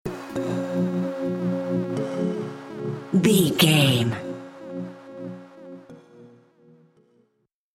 Top 40 Electronic Dance Stinger.
Aeolian/Minor
F#
groovy
dreamy
smooth
futuristic
house
electro dance
techno
synth drums
drum machine
synth leads
synth bass
upbeat